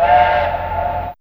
Index of /m8-backup/M8/Samples/Fairlight CMI/IIe/27Effects4
Train.wav